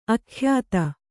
♪ akhyāta